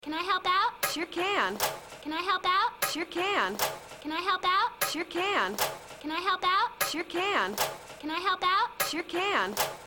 ループ音声サンプルは
同じフレーズが何度もループされます。
５回ずつ繰り返されますが
sample loop.mp3